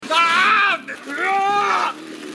Download Man Screaming sound effect for free.
Man Screaming